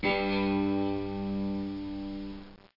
Cittern High Sound Effect
Download a high-quality cittern high sound effect.
cittern-high.mp3